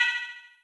OnButtonCancel.wav